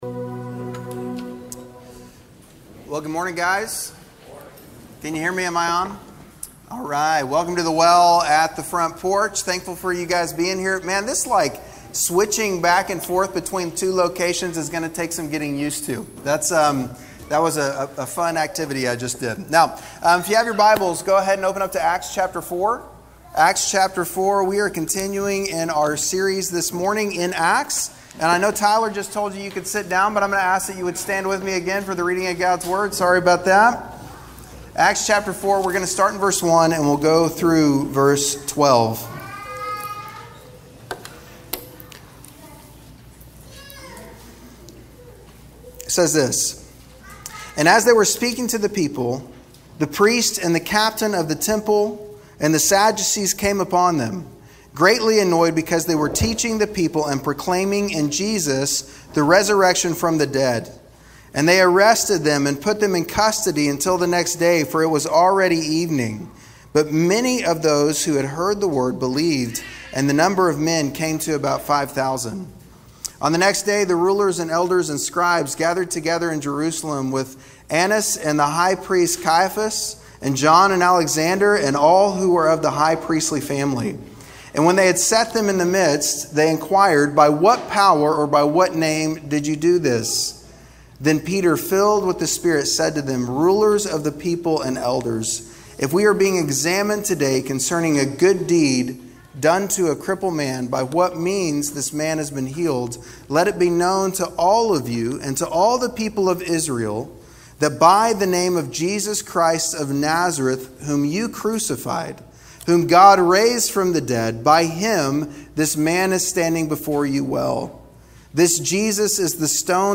The Well's July 26th Live Worship Gathering_2.mp3